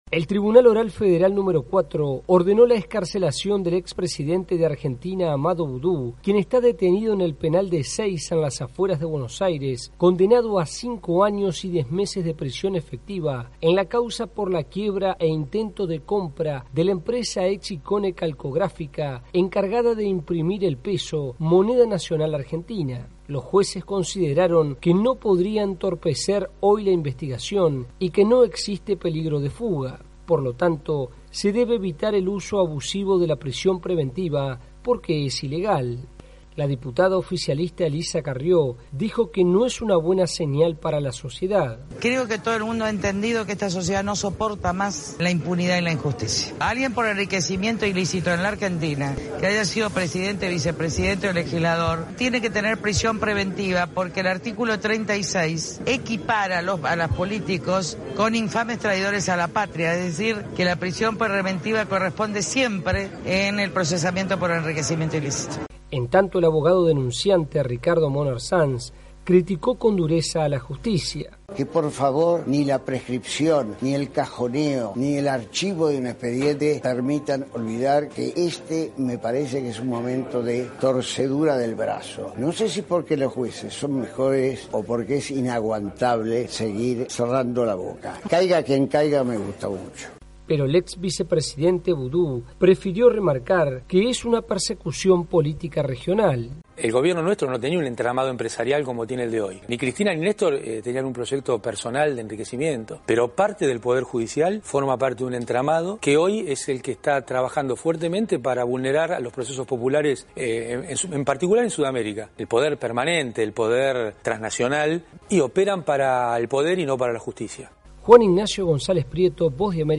VOA: Informe desde Argentina